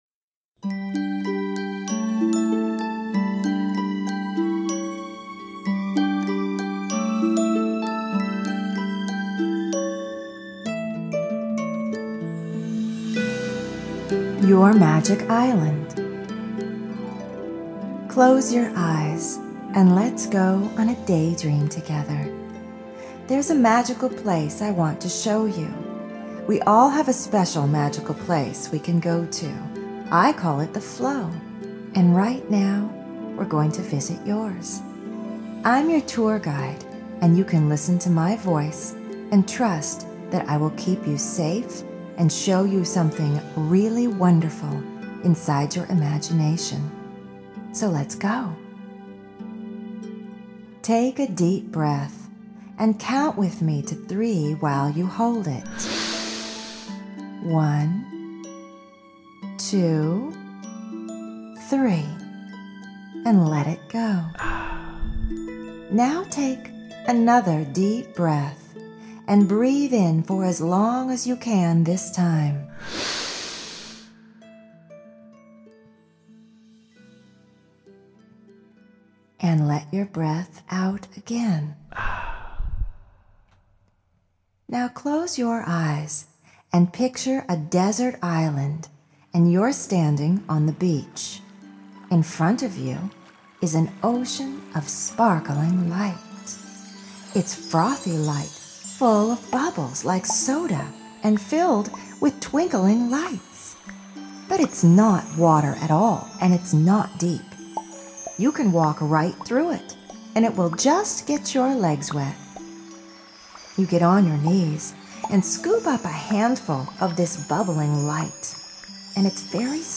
Bedtime Meditation & Positive Affirmations for Kids | Flowdreaming